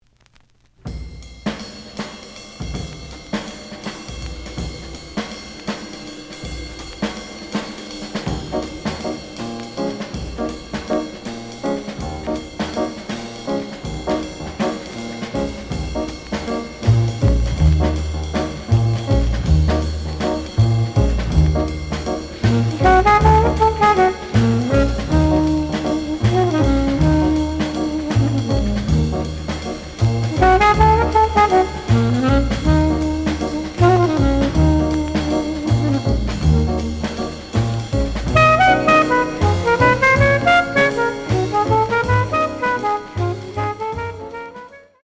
The audio sample is recorded from the actual item.
●Genre: Cool Jazz